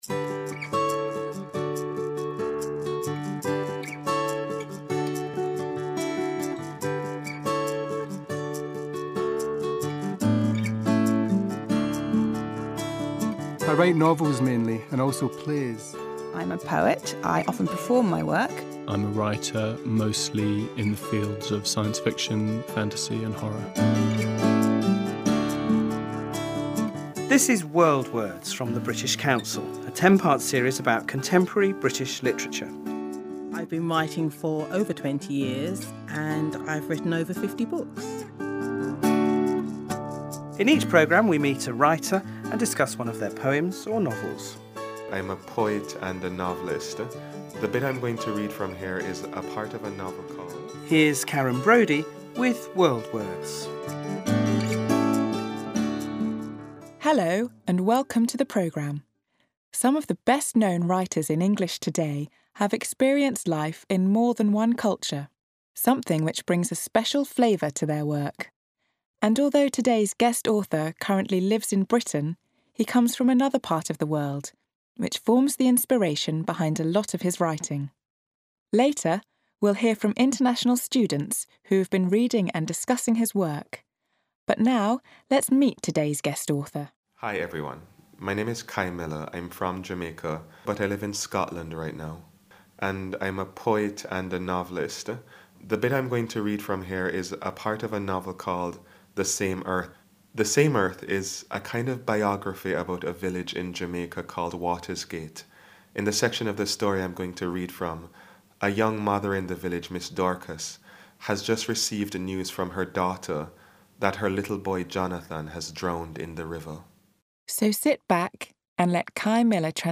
The story, as the novel, is set in Jamaica, and some of the language reflects the way that English is spoken in parts of the Caribbean.